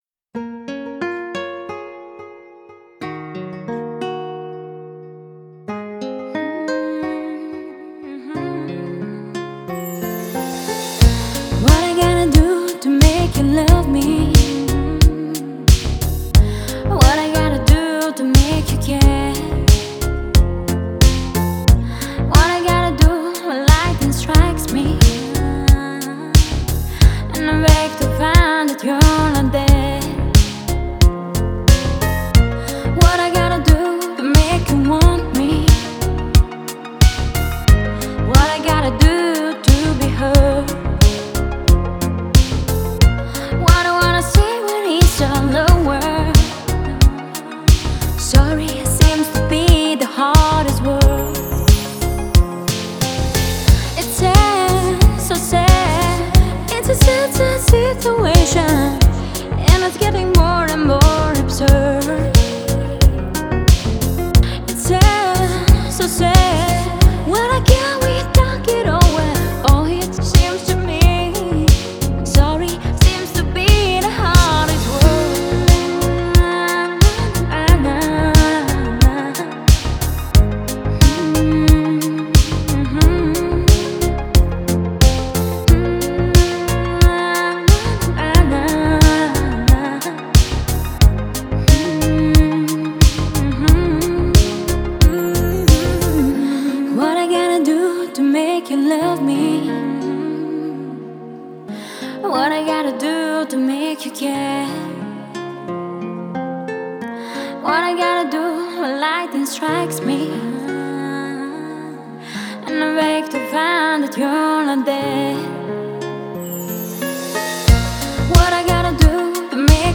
Спокойные песни
Категория: Спокойная музыка